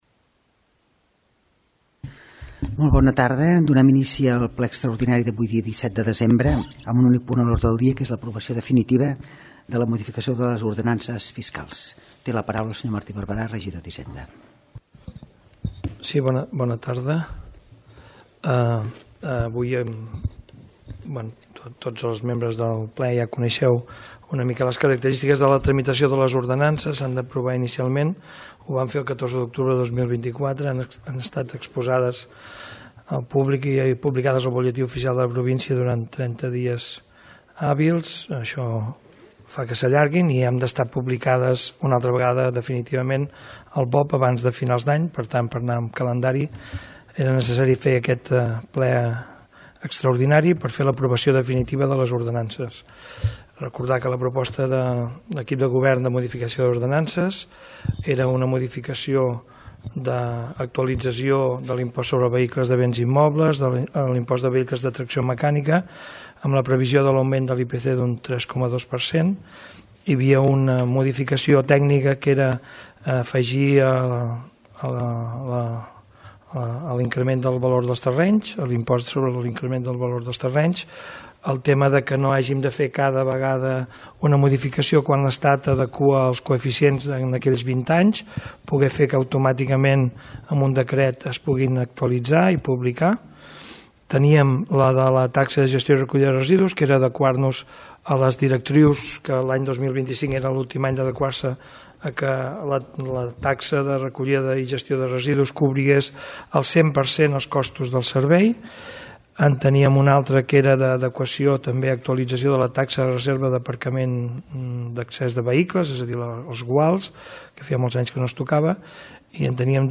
Us oferim l’enregistrament de la sessió plenària extraordinàrinia de l’Ajuntament de Valls del dimarts 17 de desembre del 2024.